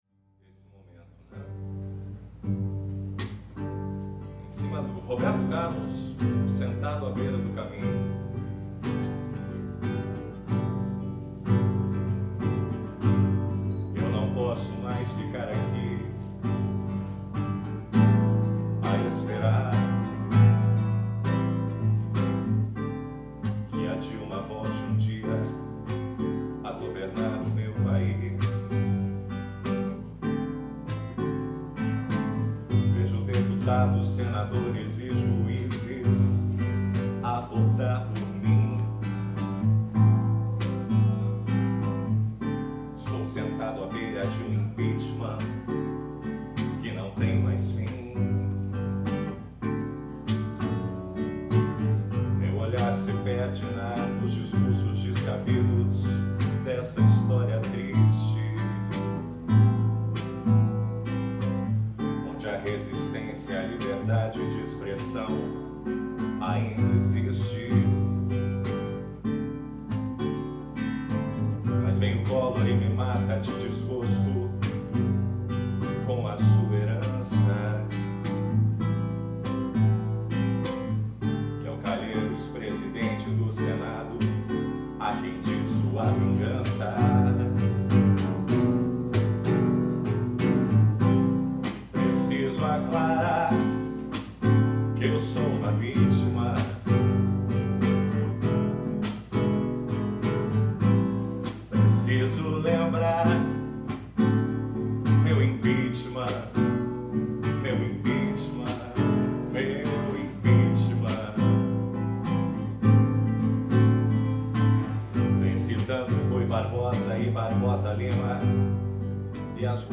Paródia 06